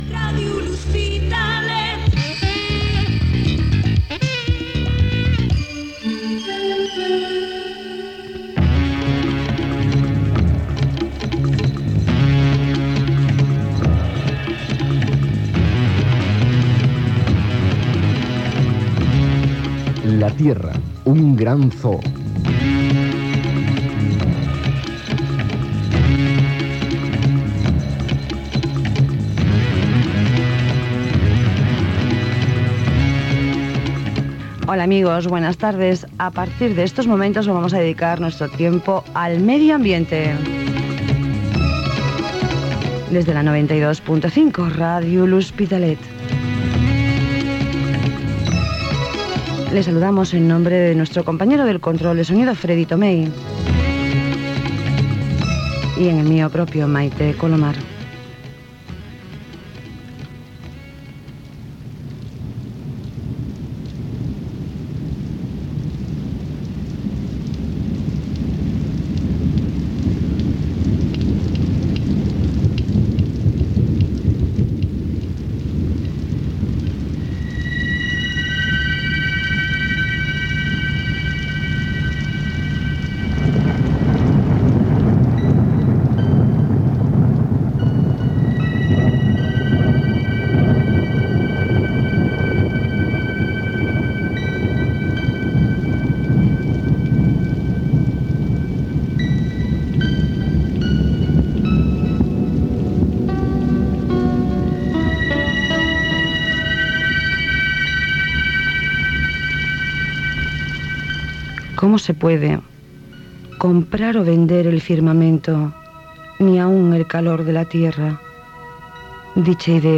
Indicatiu de l'emissora, careta del programa, presentació, carta d'un cap indi de Nord-amèrica als president dels EE.UU de l'any 1855, data, sumari
Gènere radiofònic Divulgació
Banda FM